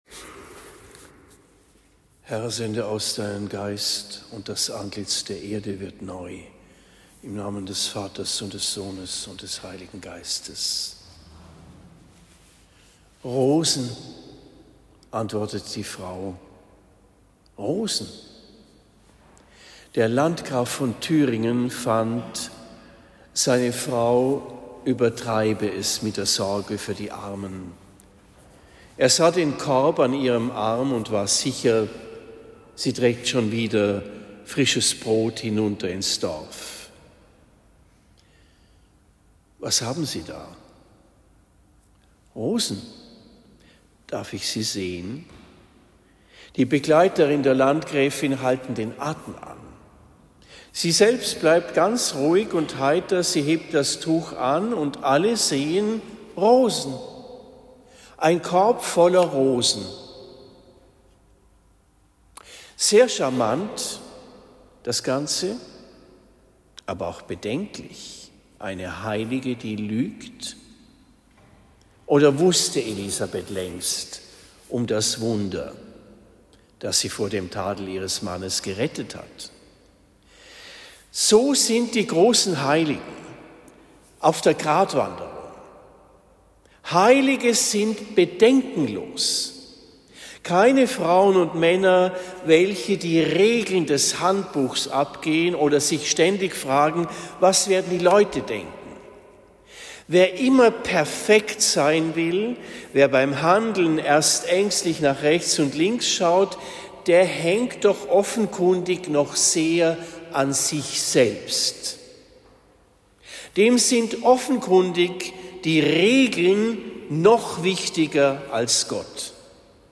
Predigt am 19. November 2025 in Marktheidenfeld St.-Laurentius